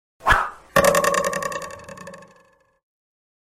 Шепот стрелы Амура (Купидон)